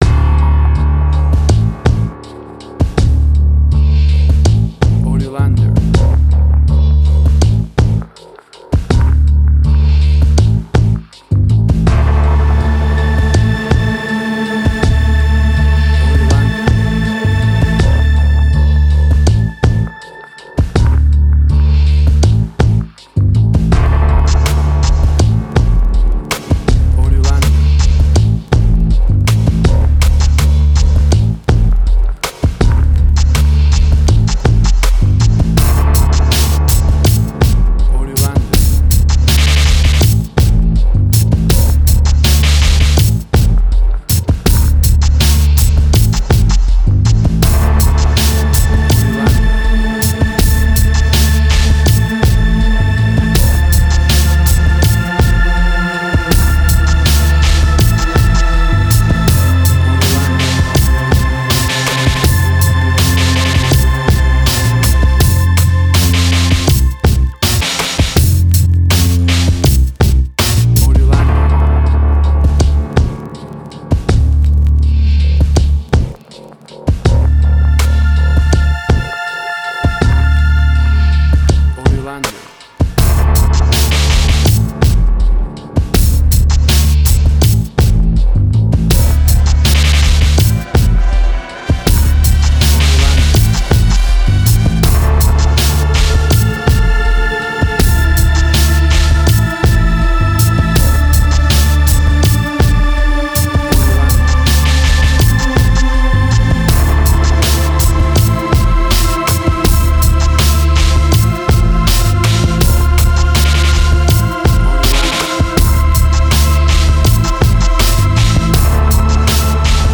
Trip Hop
Tempo (BPM): 80